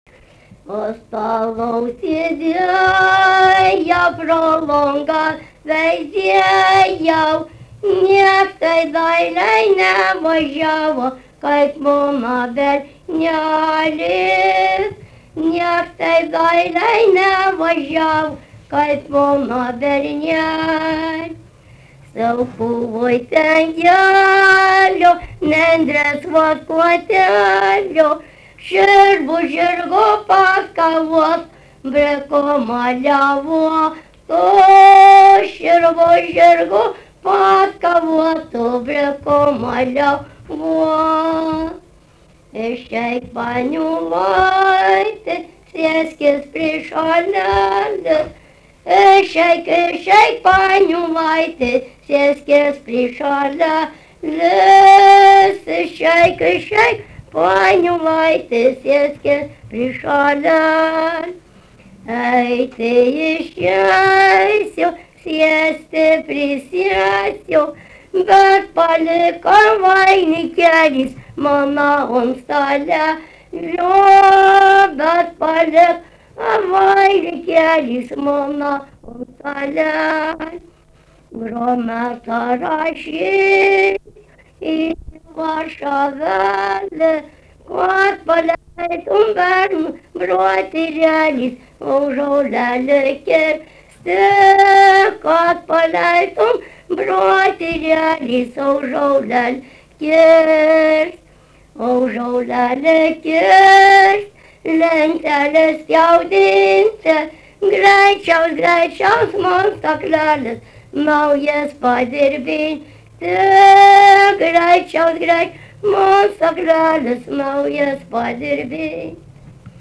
daina
Daukšiai (Skuodas)
vokalinis